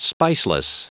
Prononciation du mot spiceless en anglais (fichier audio)
Prononciation du mot : spiceless